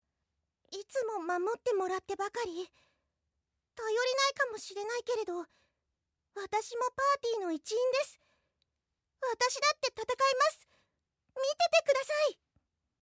魔法使いおとなしめ